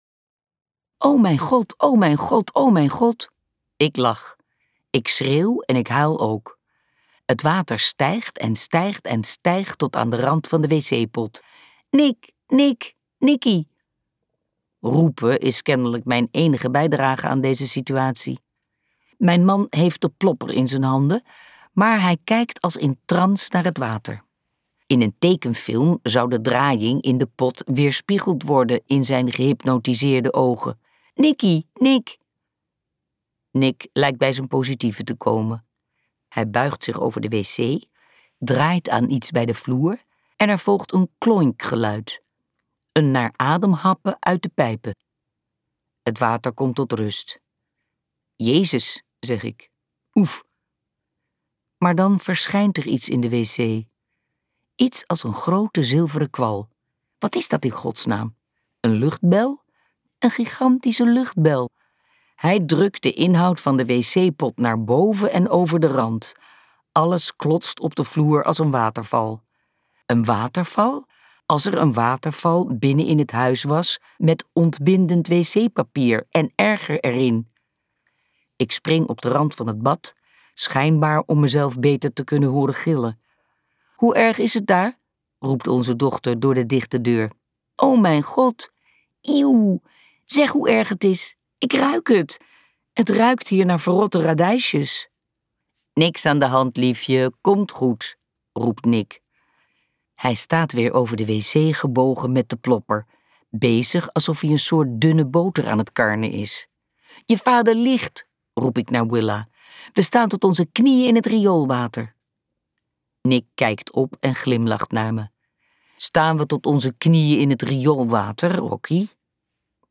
Ambo|Anthos uitgevers - Sandwich luisterboek